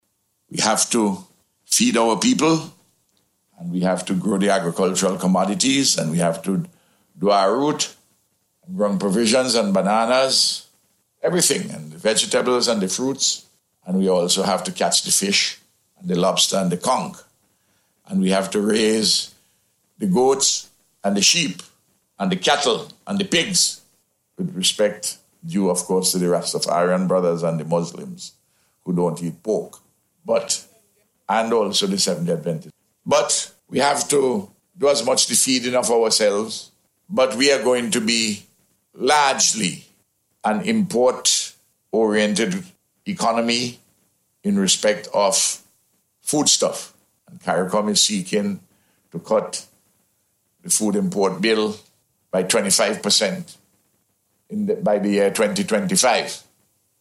Dr. Gonsalves was speaking at a ceremony in Orange Hill yesterday, to mark the completion of phase one of the Arrowroot Modernization Project.